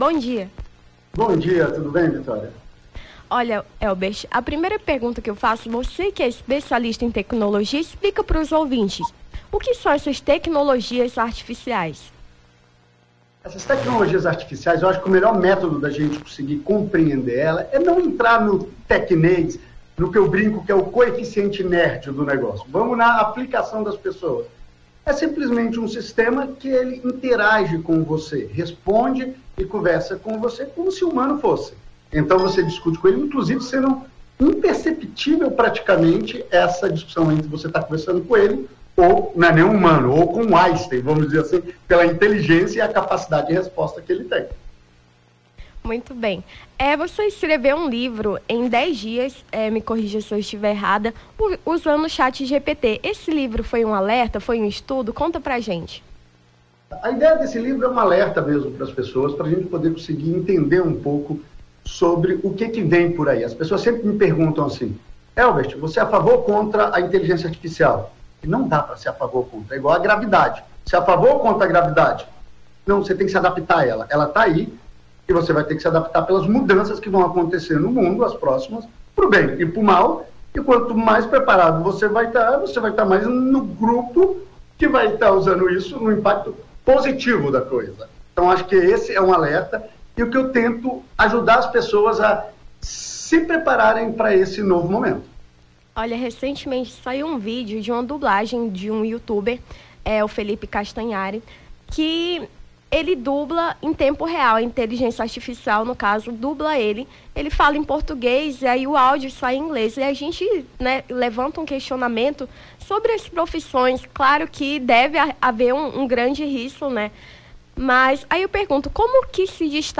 Nome do Artista - CENSURA - ENTREVISTA (ALERTA CHATGPT) 06-07-23.mp3